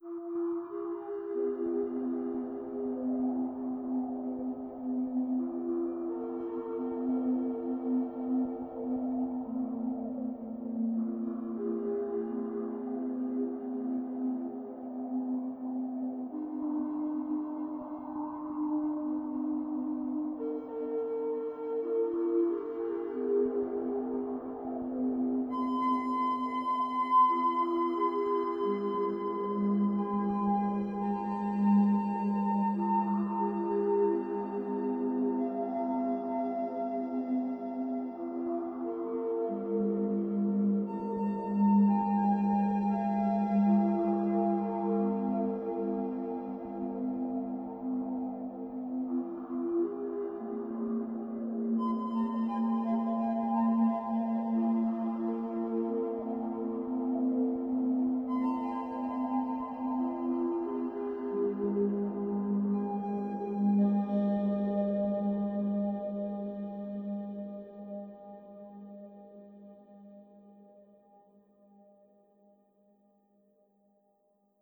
world_assets / audio / ambiance / forestBalade.wav
forestBalade.wav